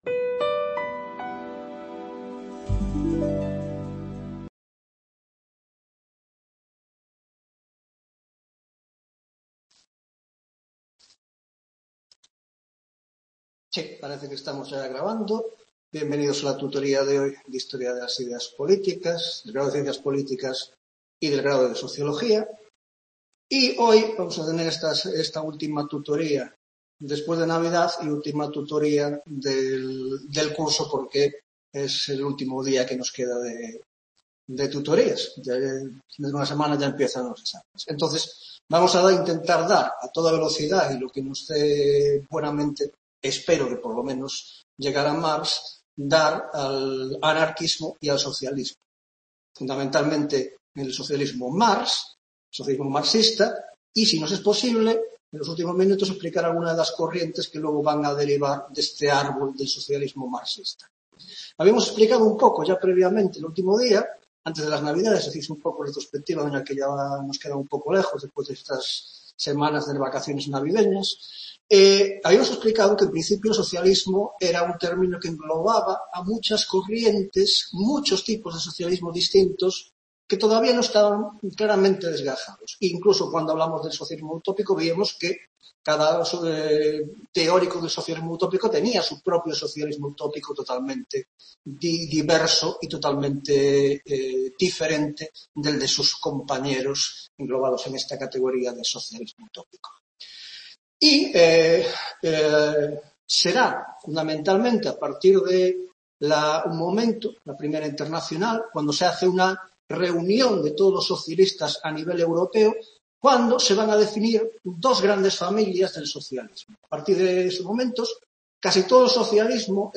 11ª Tutoría de Historia de las Ideas Políticas 2 (Grado de Ciencias Políticas)